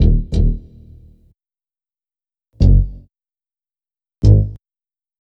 HP092BASS1-L.wav